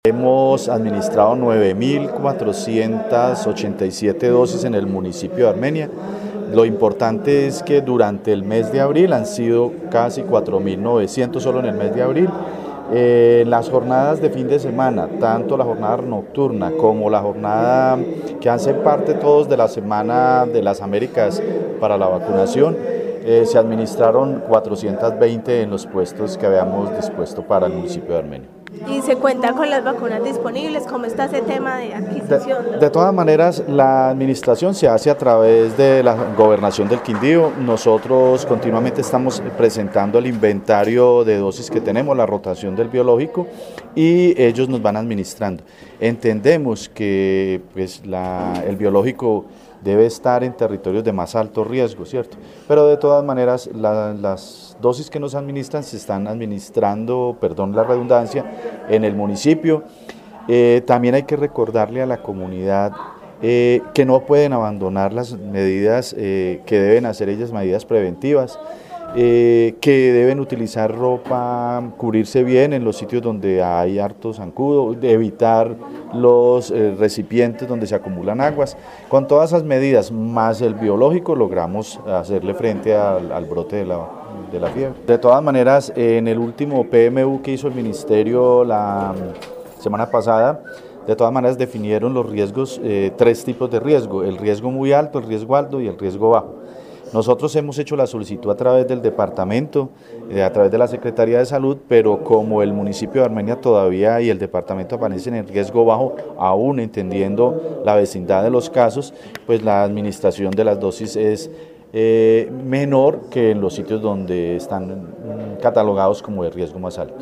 Secretario de Salud de Armenia